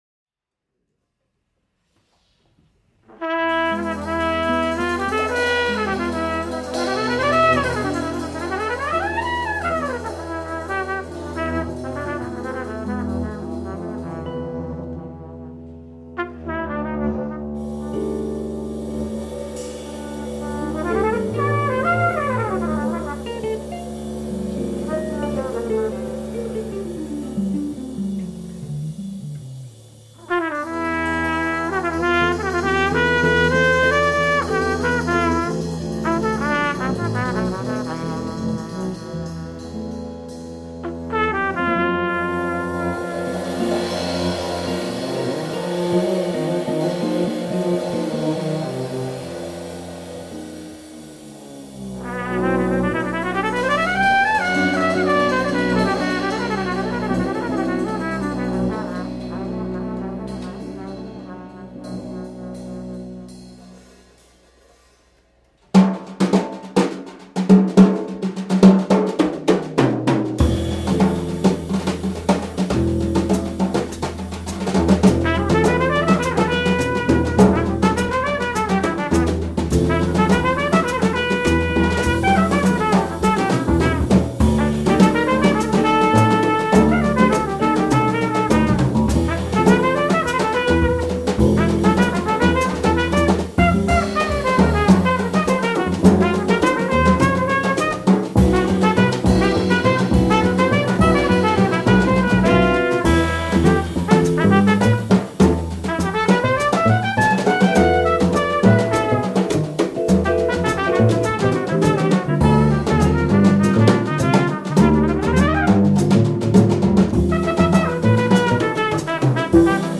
Trumpet & Flugelhorn
Guitar
Drums
Bass